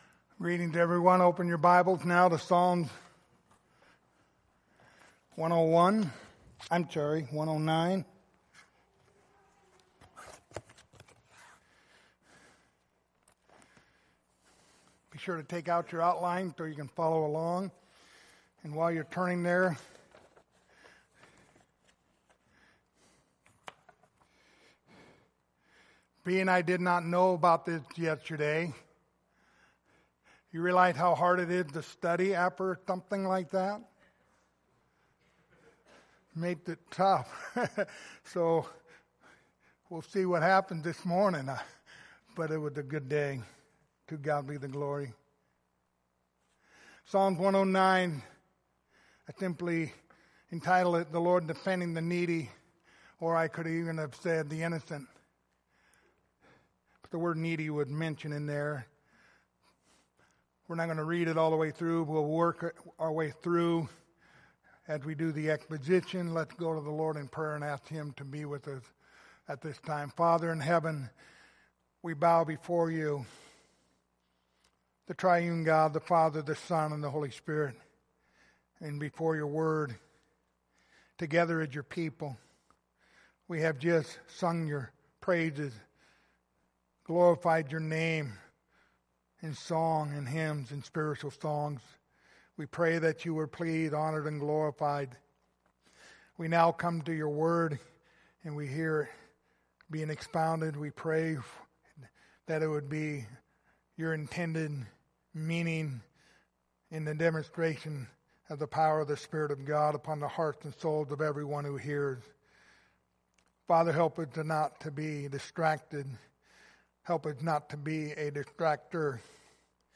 Passage: Psalms 109:1-31 Service Type: Sunday Morning